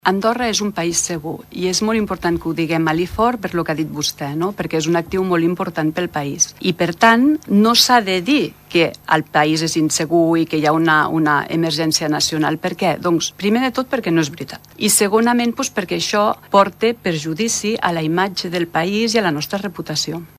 Així ho ha assegurat la ministra de Justícia i Interior, Ester Molné, en declaracions a RTVA.